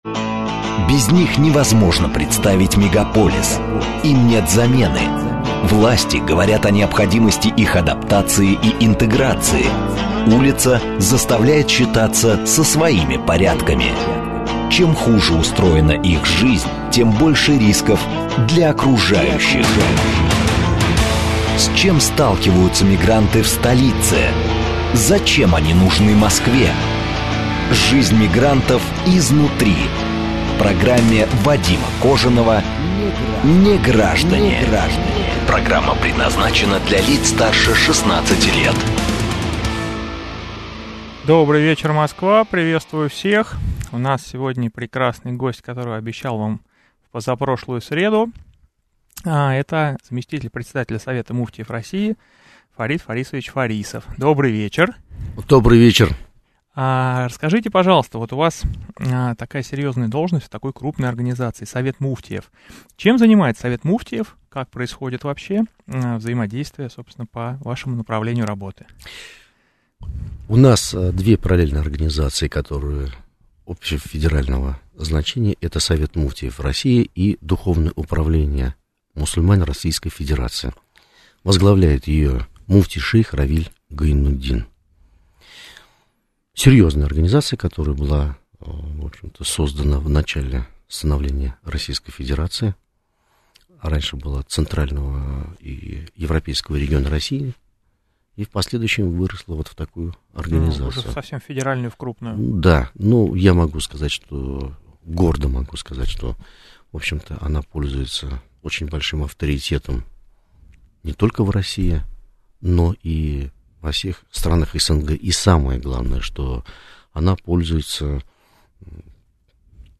Конференция со слушателями